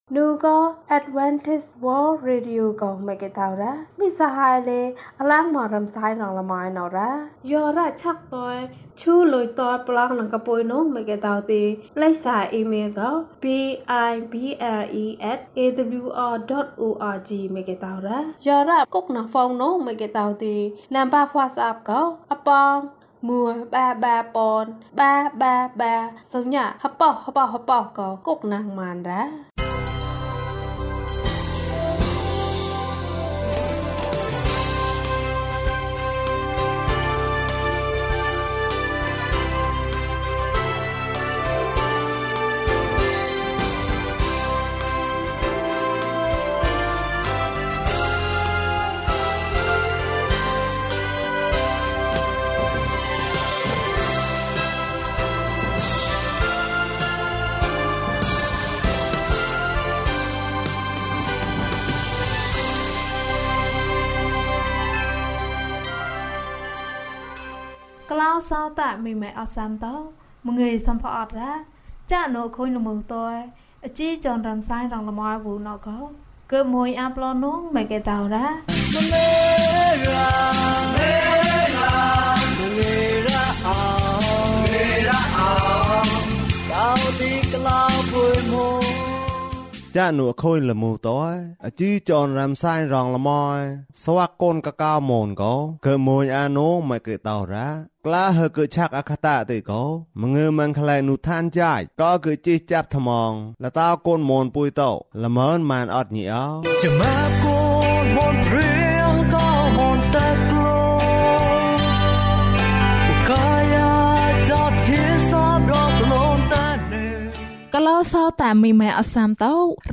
ဘုရားသခင်သည် ကျွန်ုပ်၏အသက်ကို ကယ်တင်တော်မူပါ။၀၂ ကျန်းမာခြင်းအကြောင်းအရာ။ ဓမ္မသီချင်း။ တရားဒေသနာ။